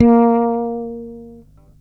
36-A#3.wav